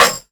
Snare (32).wav